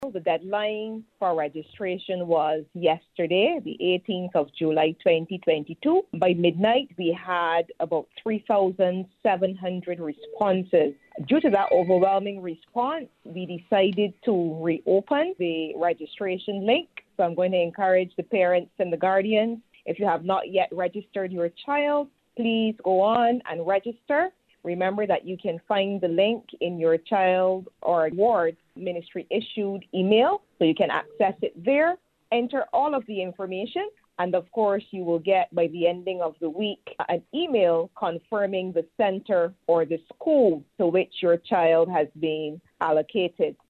Chief Education Officer, Dr. Ramona Archer-Bradshaw, tells Starcom Network News the registration deadline has also been extended.